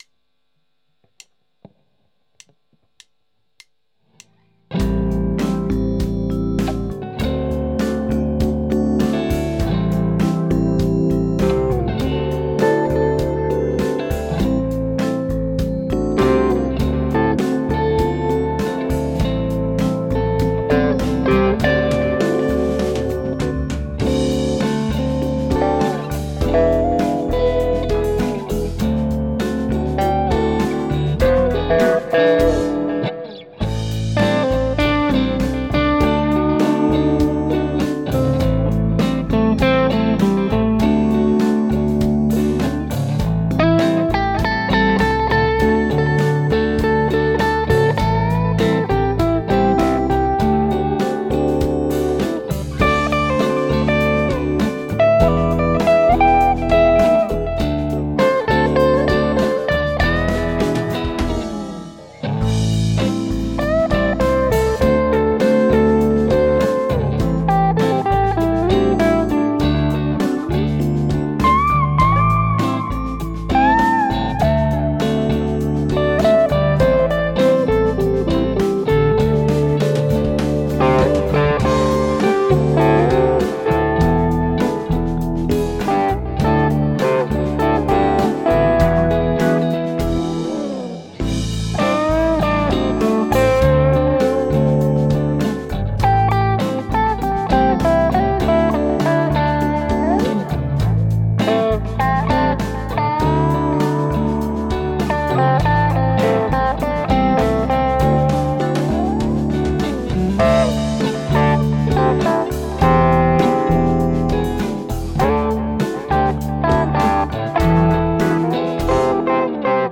Aufnahmen mit minimalem Equipment
Zum Vergrößern anklicken.... ja. ich hab mal was gemacht, da hat sich nur geändert, daß ich in Monokanäle gegangen bin: your_browser_is_not_able_to_play_this_audio (mit der Tele Thinline und der Toro, ist immer noch minimalistisches Equipment ).
Diesmal etwas ziemlich schräges, aufgenommen mit meiner Eigenbau-Gitarre, Hydrogen-Computerschlagzeug und wie immer mit Audacity your_browser_is_not_able_to_play_this_audio UUps, die Gitarre hab ich 2017 gebaut, die Saiten haben auch schon wieder 5Jahre auf dem Buckel